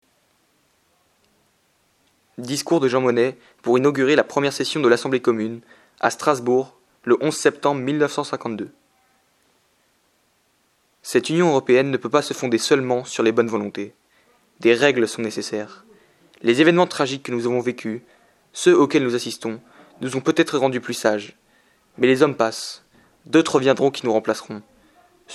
Discours de Jean Monnet (FR - intermédiaire) | SLinguix - Erasmus+
discours_Strasbourg.mp3